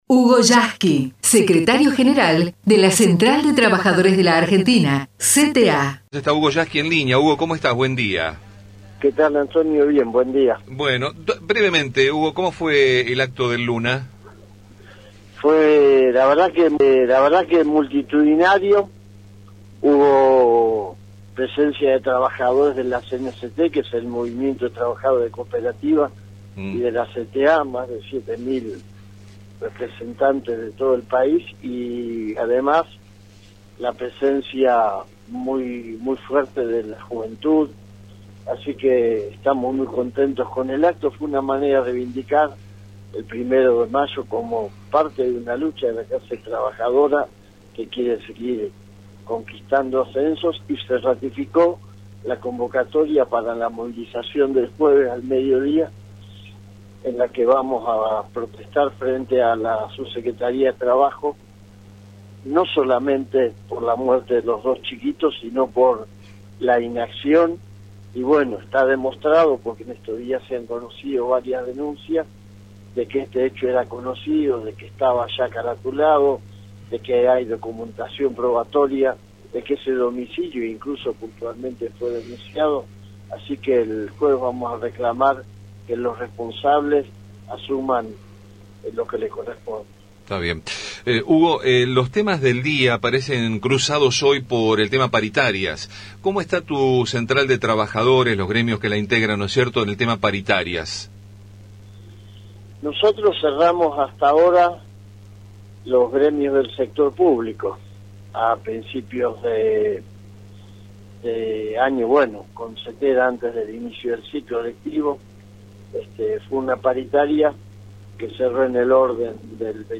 HUGO YASKY entrevistado en RADIO AMÉRICA